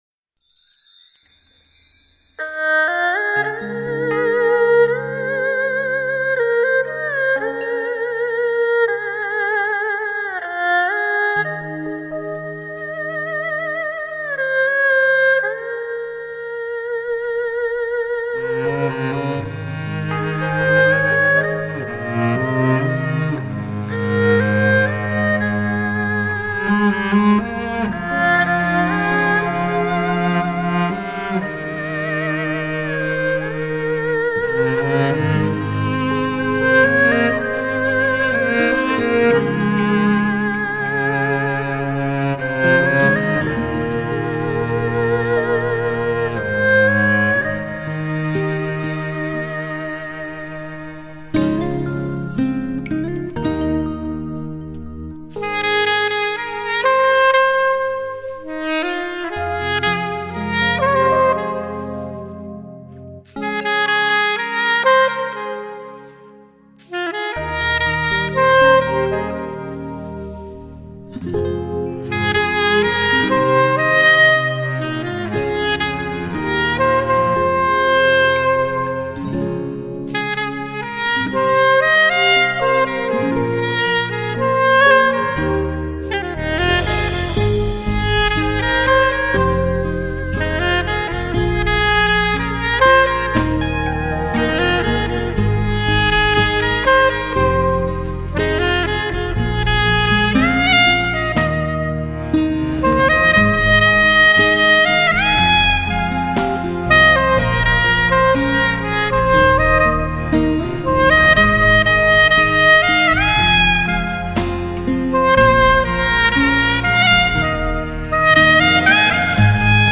萨克斯风演奏经典流行曲
2胡加大提琴的民乐，享受啦。